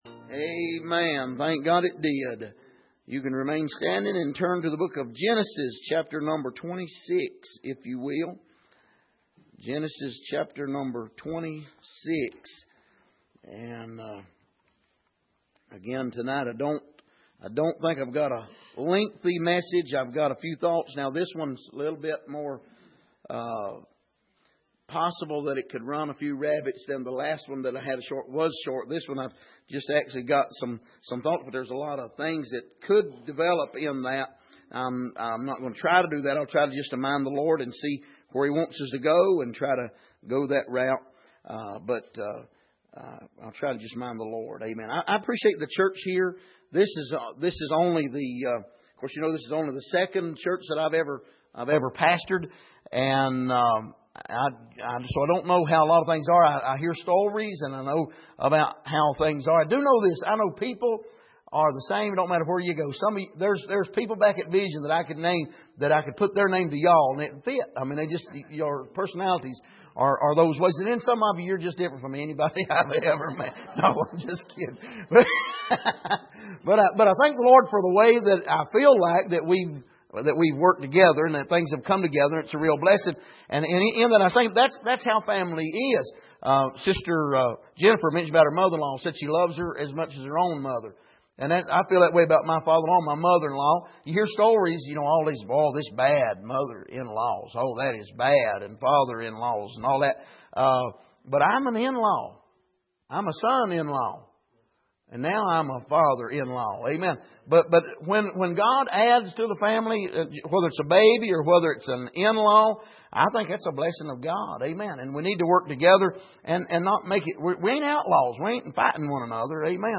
Passage: Genesis 26:17-25 Service: Sunday Evening